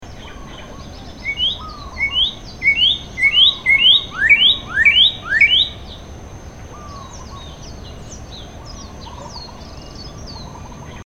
A bird singing outside the window.